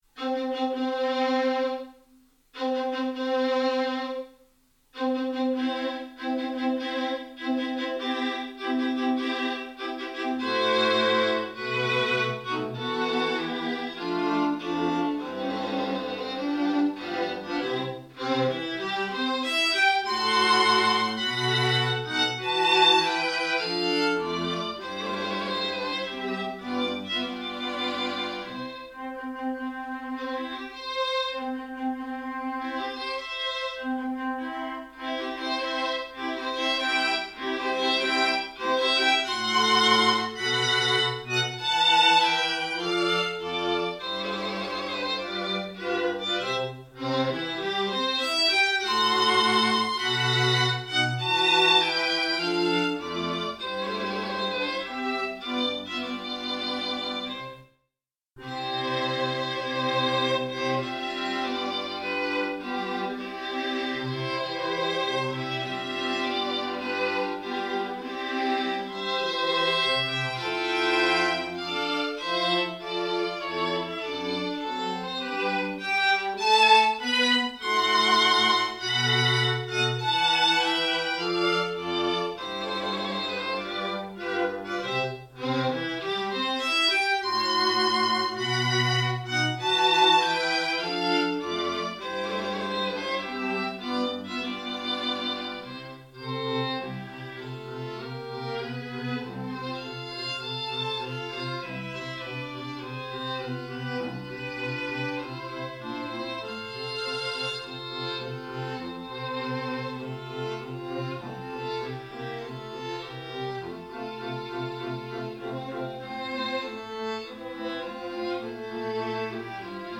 streichquartett-amore-hochzeitsmarsch-mendelssohn.mp3